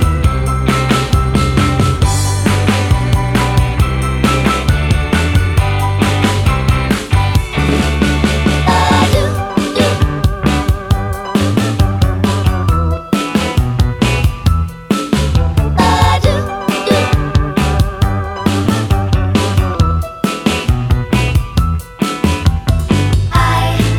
Duet Version Pop (2000s) 3:13 Buy £1.50